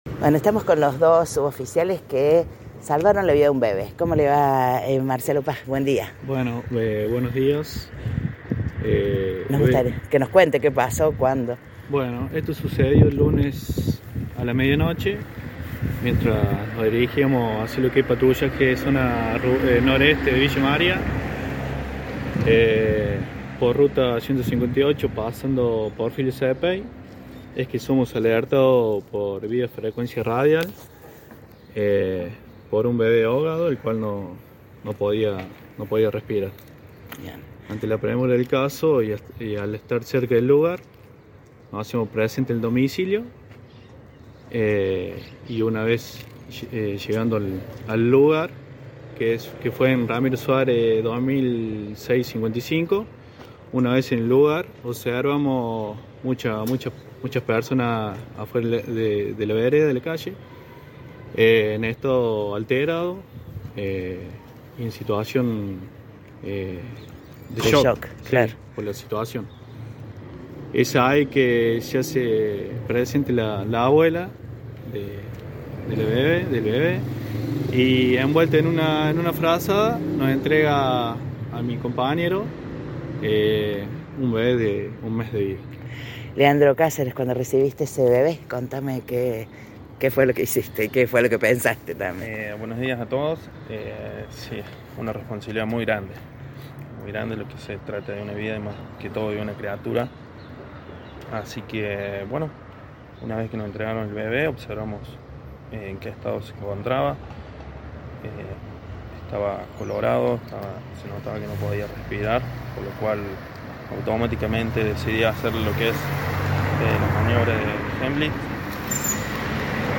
Audio: declaraciones